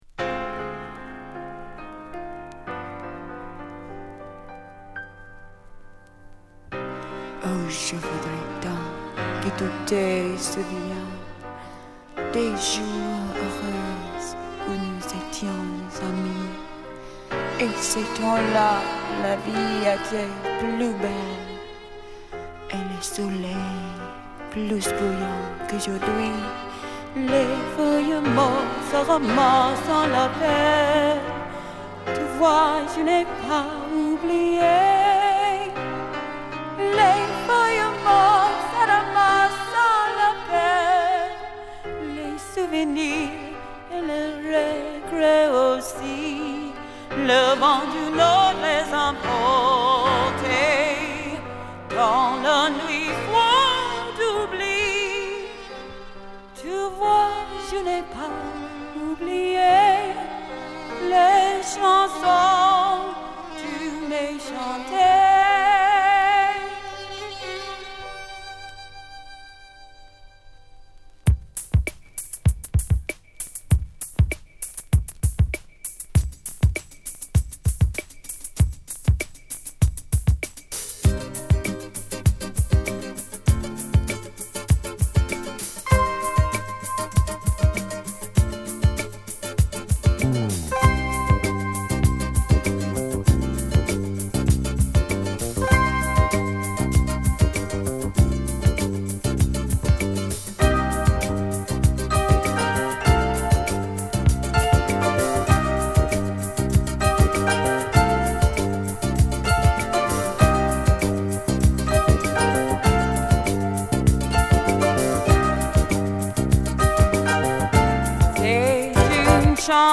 録音はSigma Sound Studioでバックも勿論フィリー勢が担当。